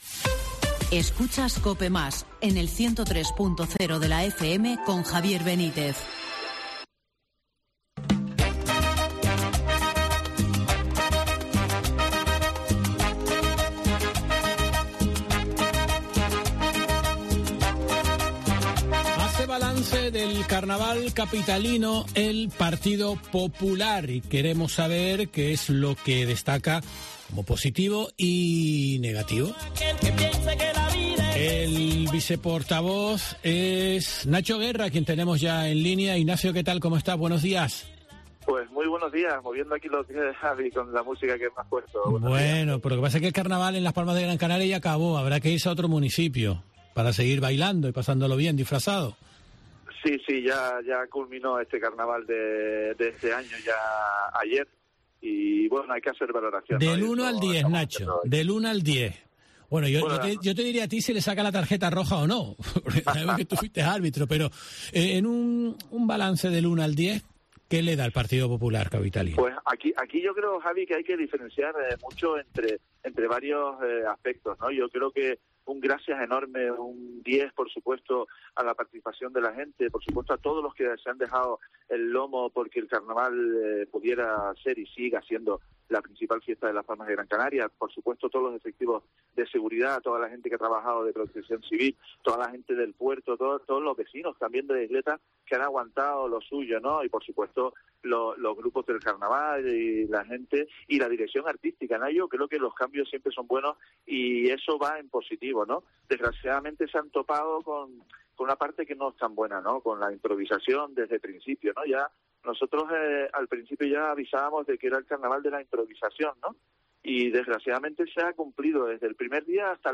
Ignacio Guerra, viceportavoz municipal del Partido Popular